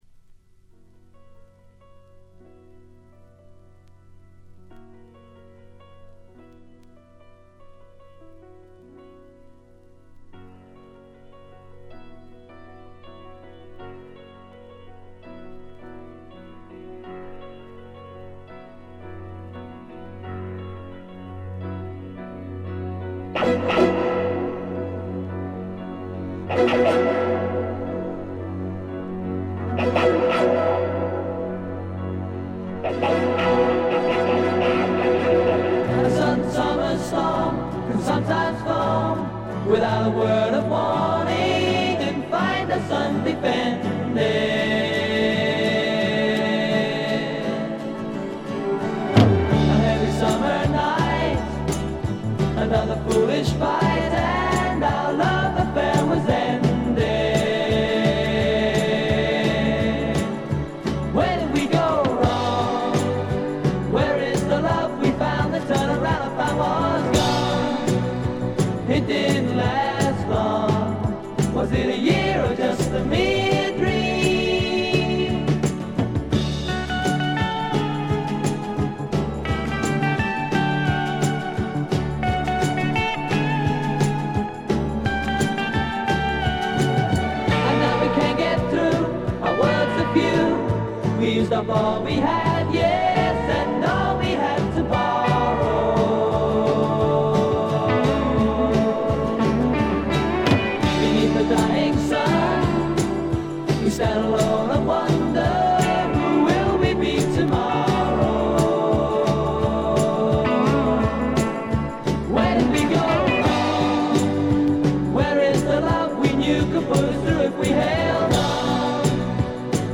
英国のポップ・デュオ
いかにも英国らしい繊細で哀愁感漂う世界がたまらないです。
試聴曲は現品からの取り込み音源です。